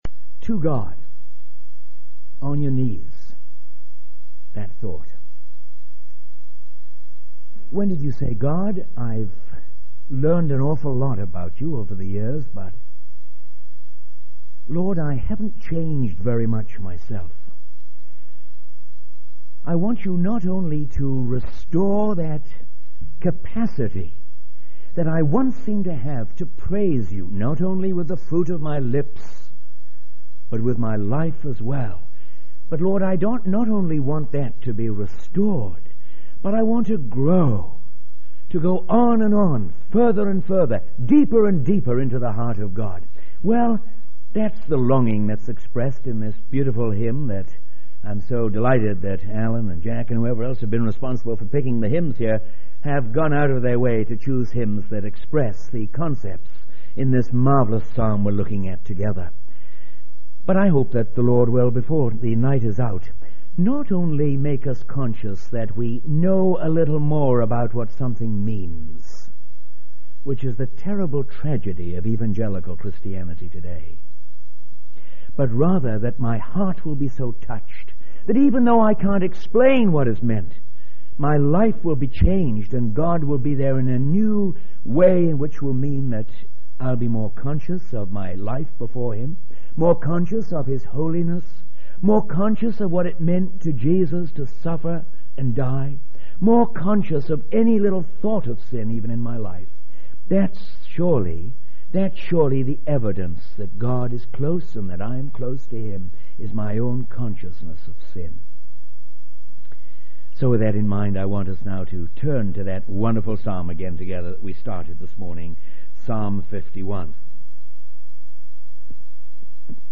In this sermon, the speaker focuses on the importance of confessing our sins to God. He emphasizes that keeping our sins hidden will ultimately lead to failure and a paralyzed spiritual life. The first verse discussed is Psalm 51:3, which highlights the presence of a condemning conscience when we are aware of our transgressions.